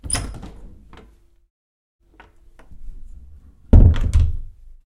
住宅 " 卧室门
Tag: 卧室 打开 关闭